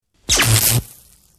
Звуки микроволновой печи
Звук замкнуло в микроволновке